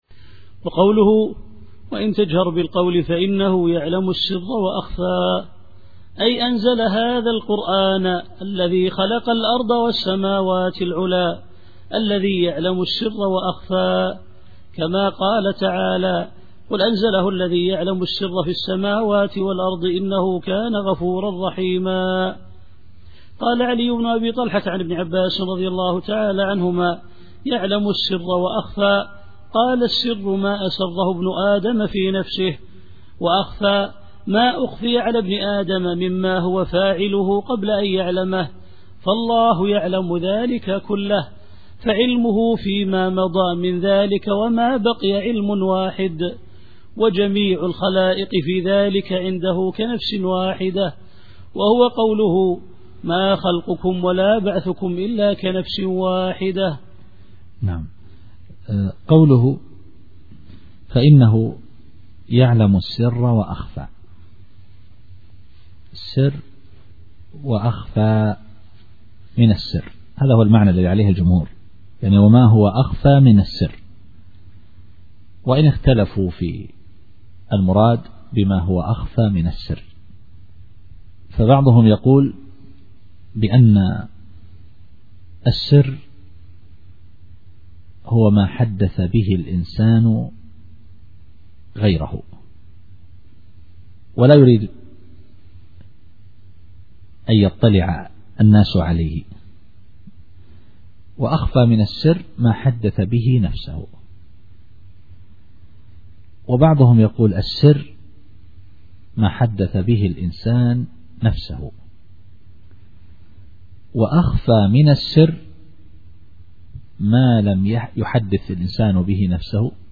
التفسير الصوتي [طه / 7]